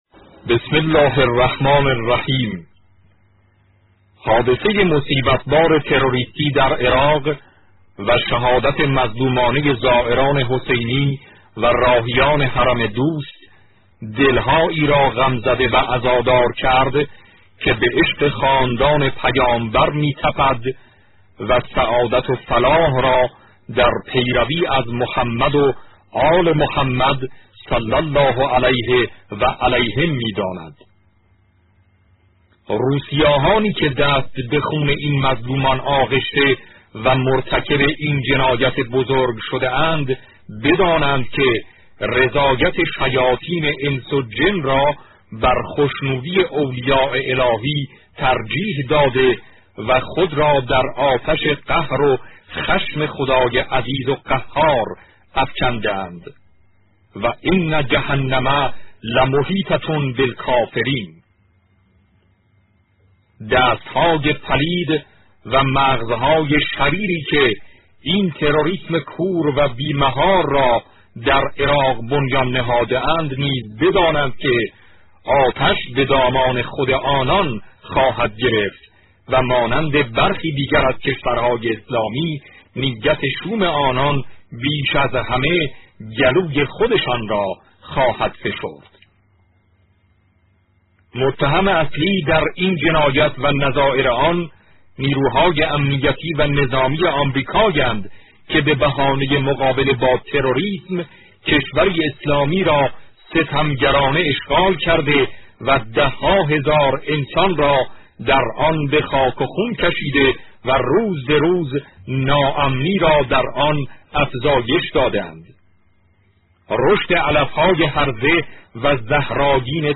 صوت کامل بیانات
پيام تسلیت رهبر معظم انقلاب اسلامى در پی حادثه ی مصيبت بار تروريستی در عراق